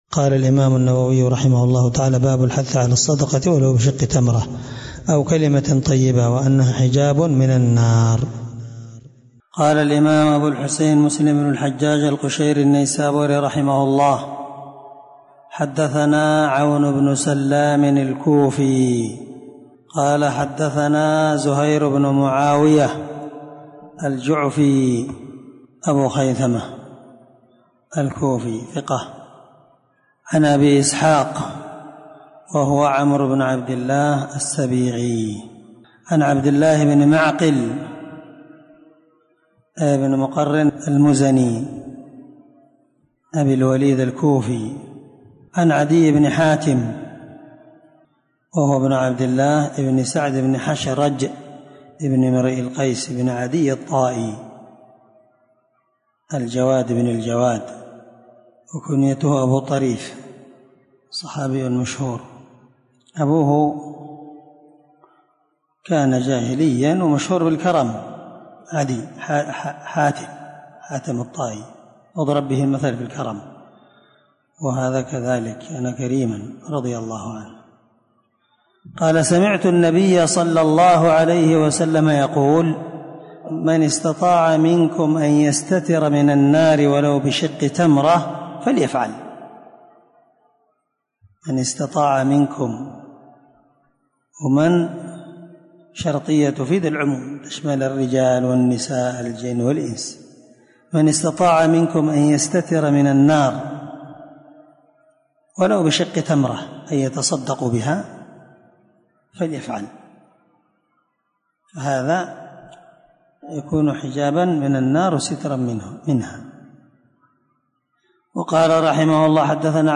620الدرس 28من شرح كتاب الزكاة حديث رقم(1016) من صحيح مسلم